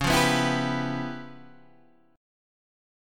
C# 7th Sharp 9th Flat 5th